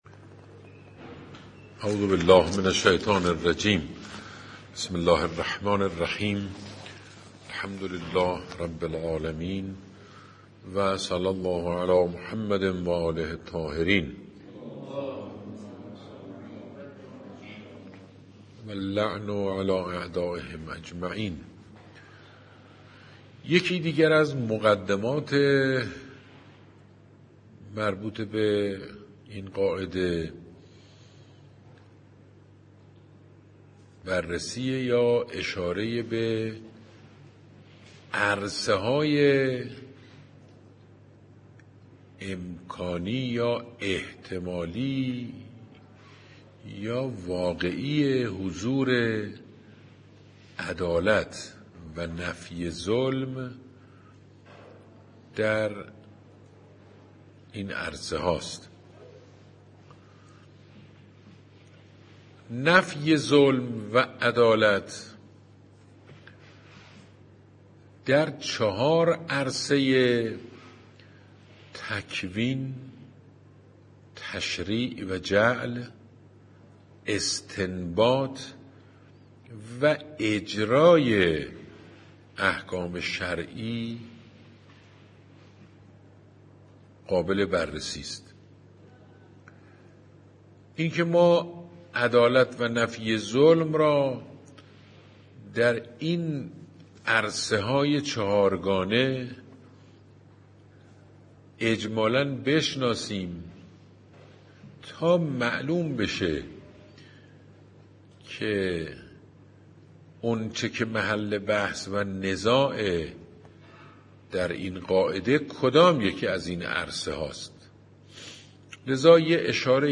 قواعد فقهیه؛ جلسه یازدهم؛ مقدمات؛ 6؛ عرصه‌های چهارگانه حضور عدالت - پایگاه اطلاع رسانی آیت الله سید مجتبی نورمفیدی